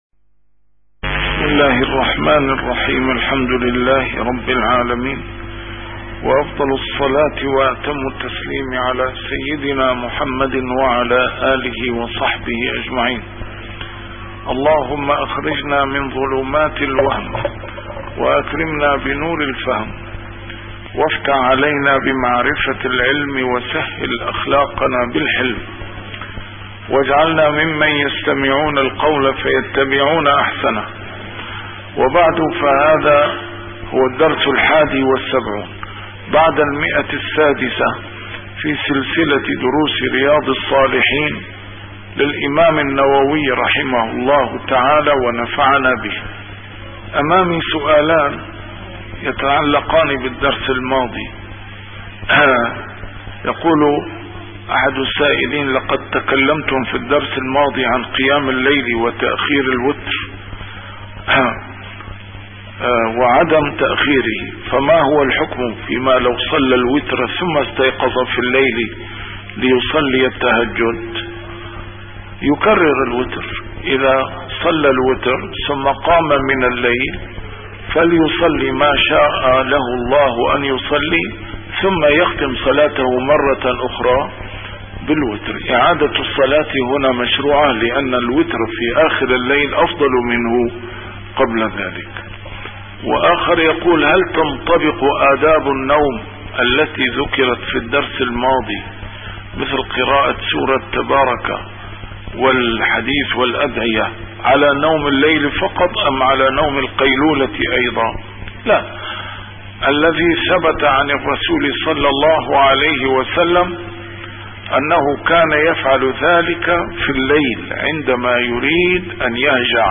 A MARTYR SCHOLAR: IMAM MUHAMMAD SAEED RAMADAN AL-BOUTI - الدروس العلمية - شرح كتاب رياض الصالحين - 671- شرح رياض الصالحين: آداب النوم والاضطجاع